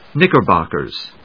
音節knick・er・bock・ers 発音記号・読み方
/níkɚbὰkɚz(米国英語), níkəb`ɔkəz(英国英語)/